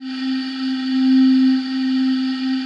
PAD 48-2.wav